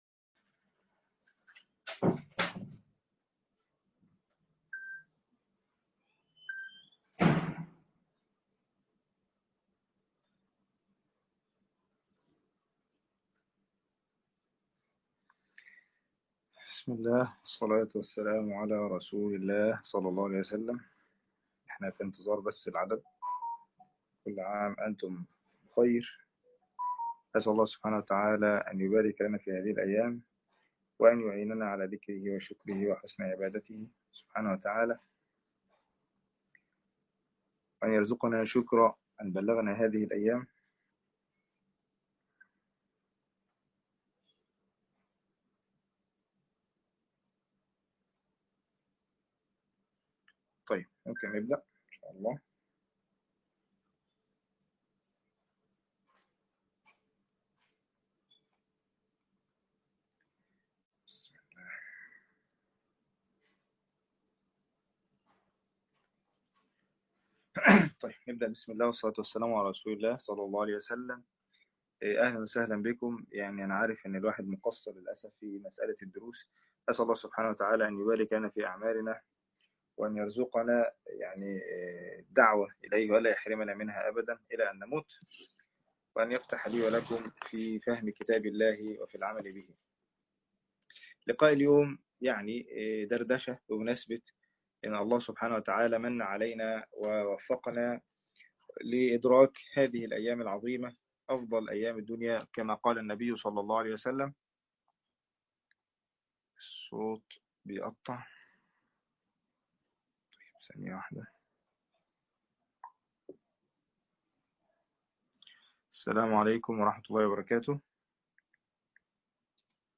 ارتباط مواسم الطاعات لقاء مباشر العشر من ذى الحجة